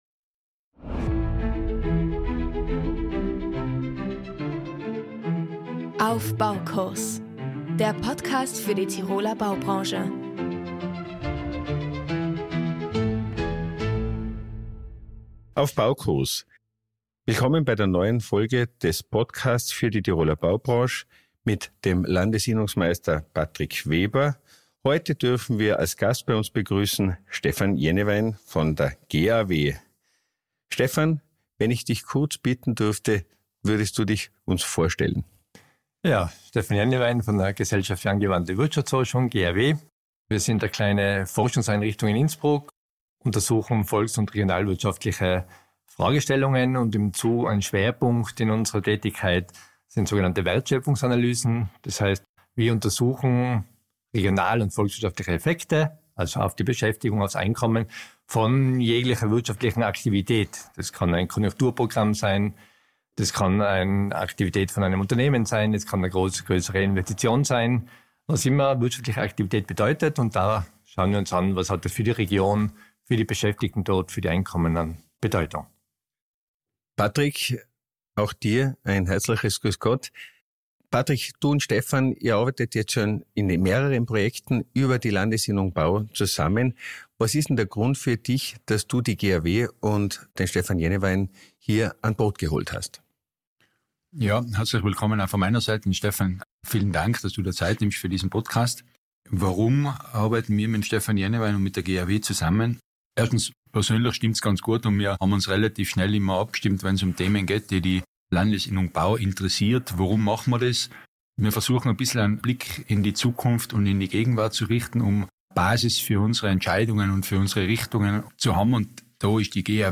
Die Diskussion wird in bewährter Weise moderiert